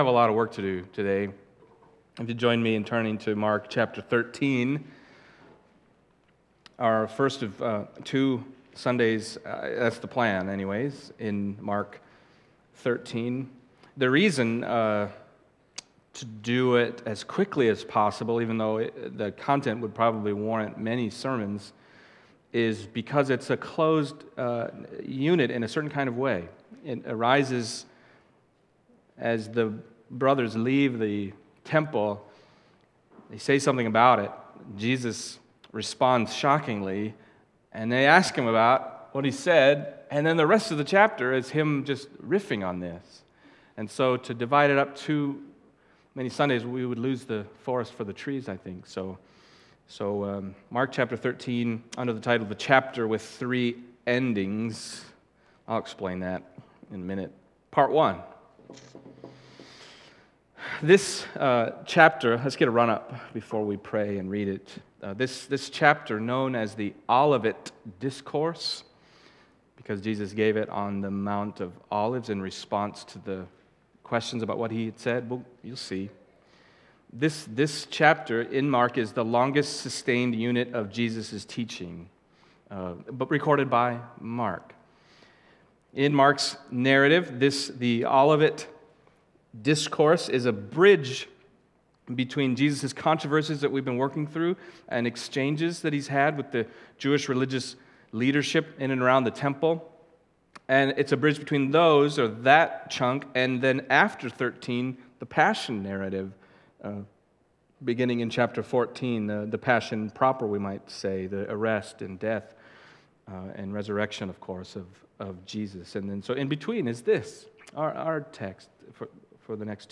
Passage: Mark 13:1-13 Service Type: Sunday Morning